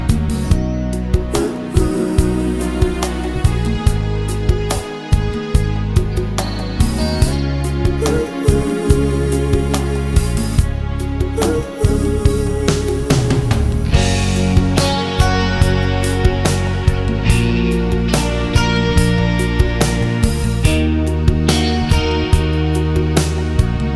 No Piano Pop (1970s) 3:56 Buy £1.50